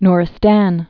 (nrĭ-stăn, -stän)